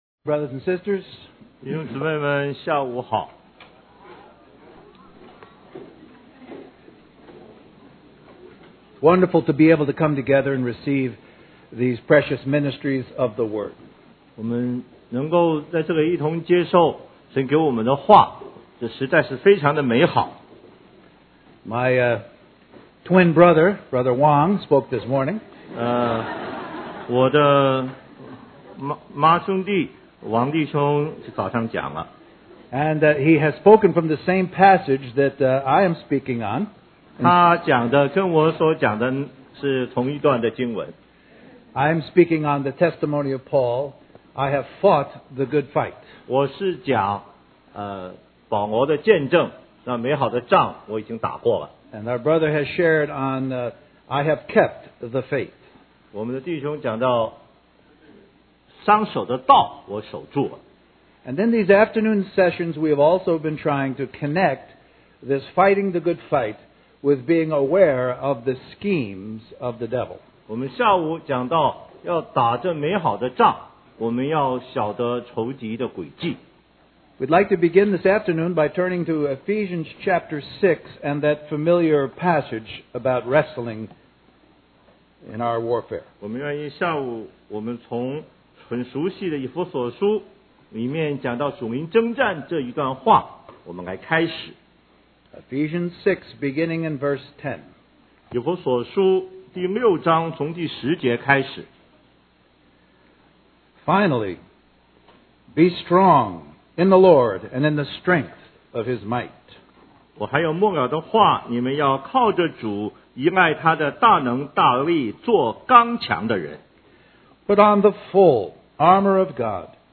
West Coast Christian Conference